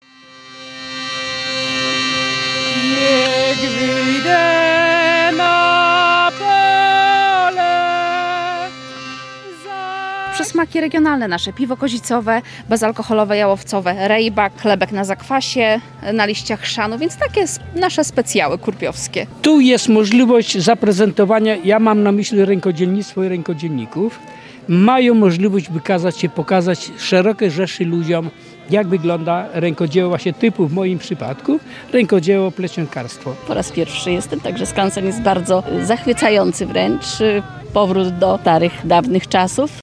Jak co roku przybyło wielu zainteresowanych z całego regionu: